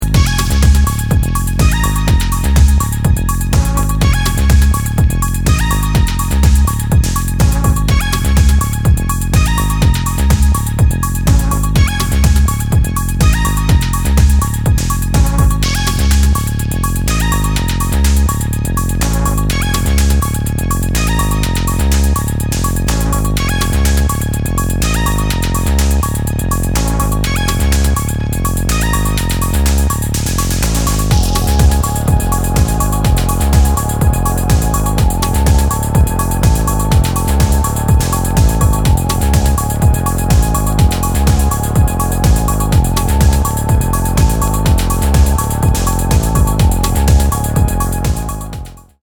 ビビッドなリフやパッド、ヘヴィーなベースをワイドに響かせるアシッド・ブリープ
全体的にかなりソリッドにまとめられ